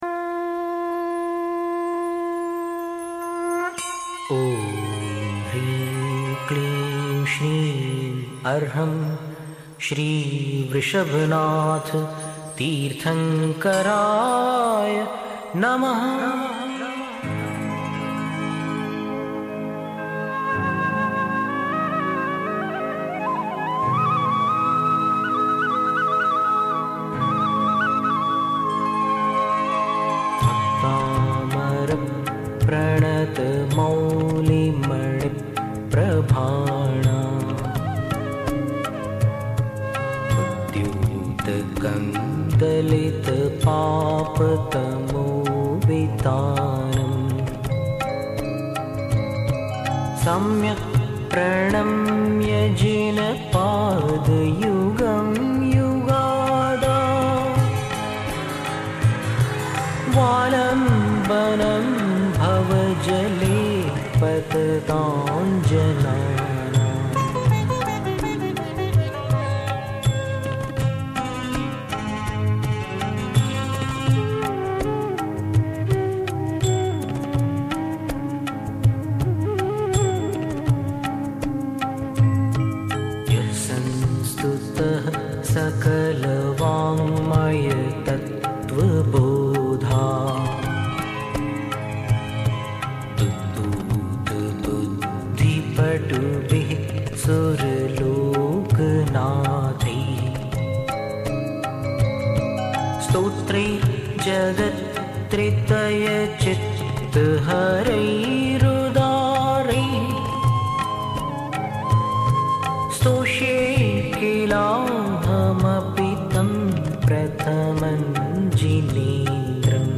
ये वसंत-तिलका छंद में लिखा गया है!